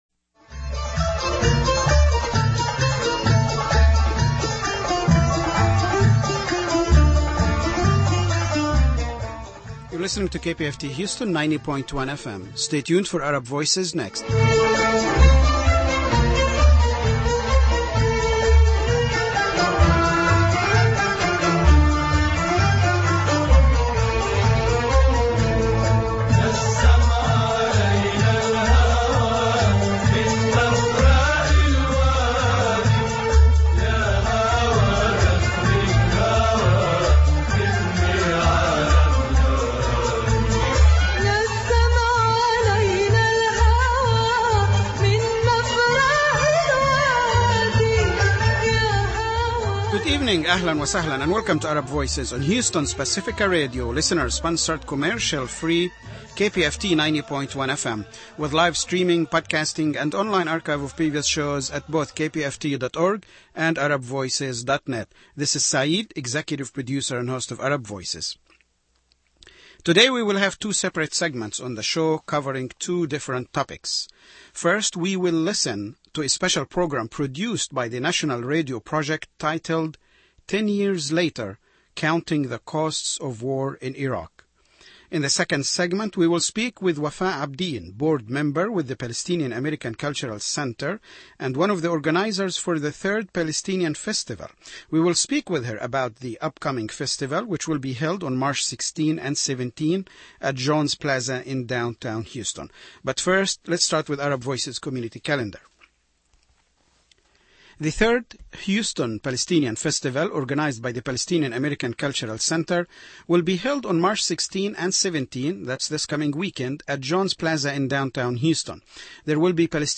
Tune in and listen to Arab Voices for the latest news, views and live discussions about the Middle East.